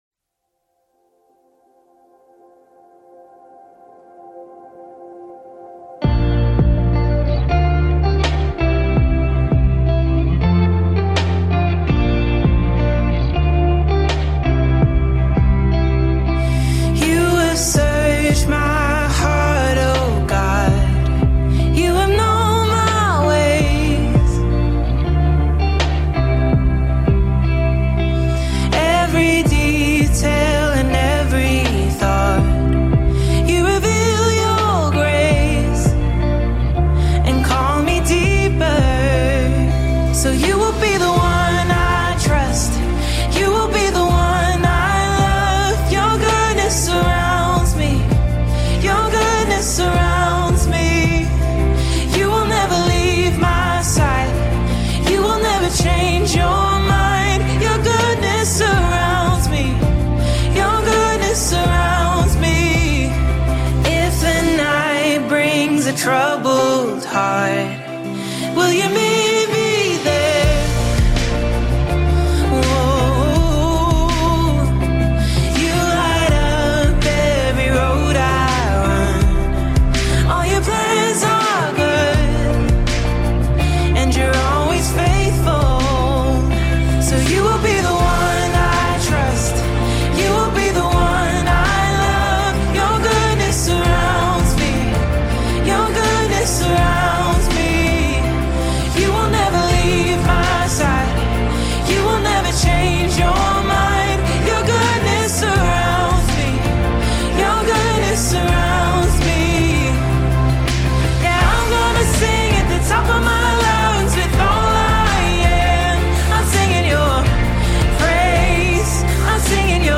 Australian praise and worship music group
spirit lifting single